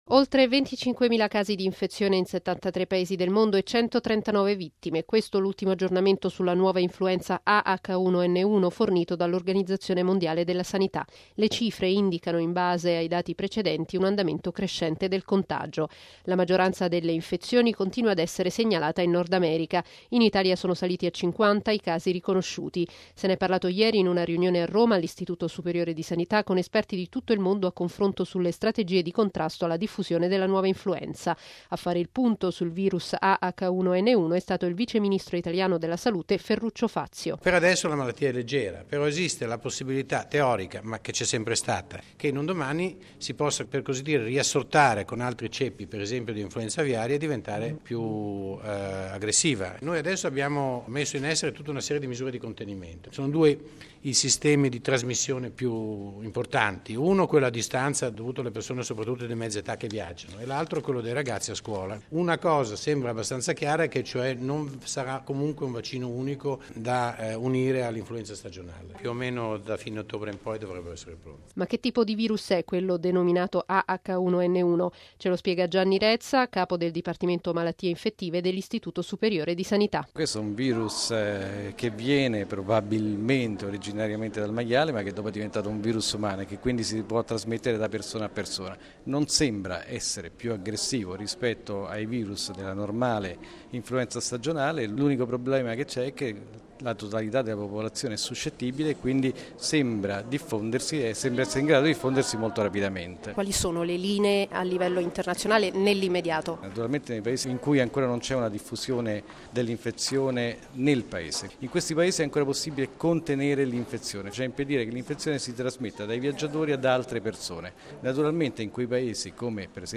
◊   Con l’obiettivo di fare il punto sul virus A/H1N1 e per valutare le strategie possibili sul contrasto alla diffusione della nuova influenza, si sono riuniti ieri a Roma, all’Istituto superiore di sanità, esperti di tutto il mondo in un confronto sulle strategie intraprese in Italia e a livello internazionale. A presiedere l’incontro, il viceministro italiano della Salute, Ferruccio Fazio.